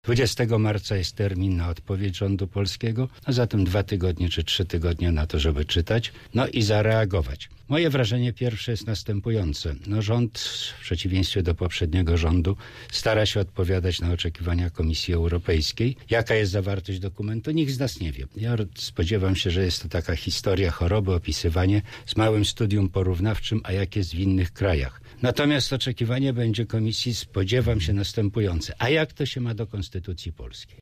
Relacje z Polski Brukselą – jednym z tematów Rozmowy Punkt 9.